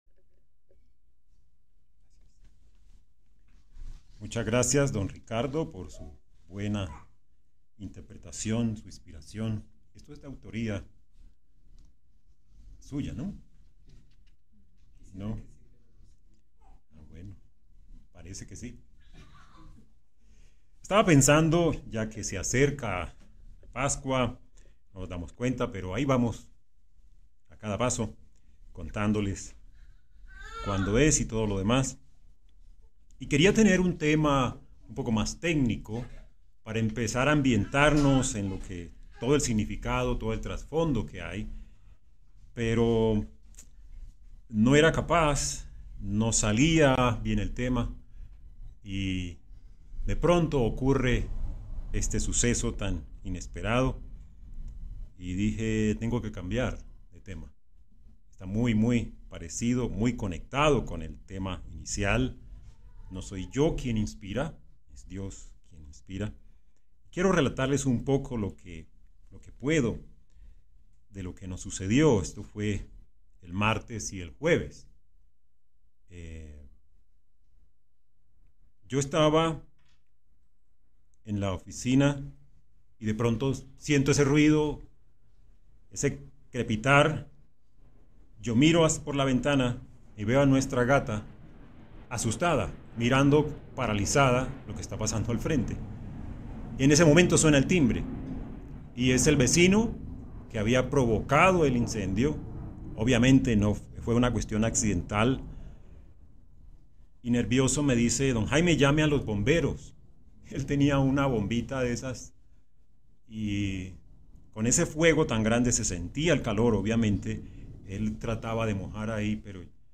Given in Santa Rosa del Sur